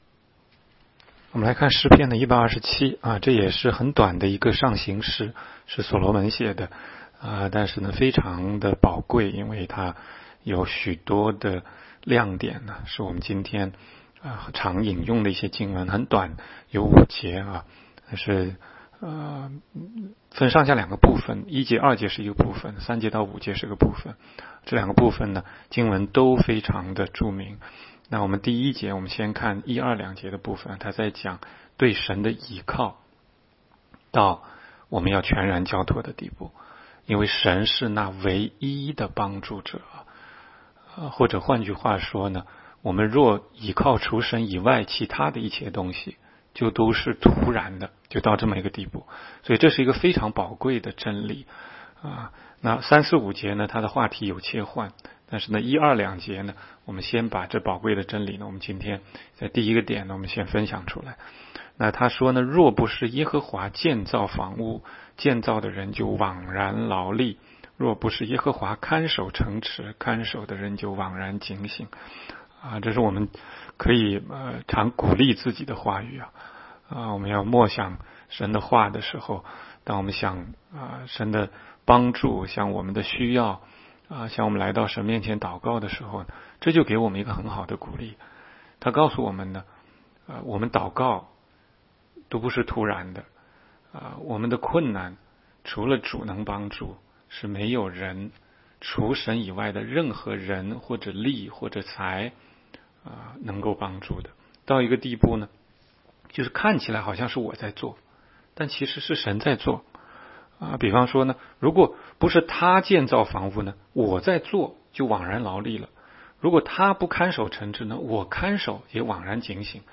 16街讲道录音 - 每日读经 -《 诗篇》127章